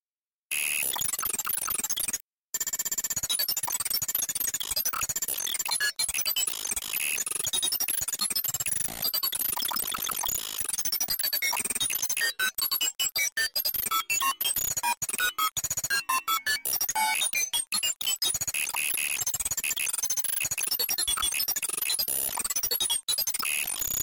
Звуки полиграфа
Аудиоэффект полиграфа: Оптимальное решение для анализа данных на мобильных устройствах